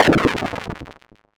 boom_b.wav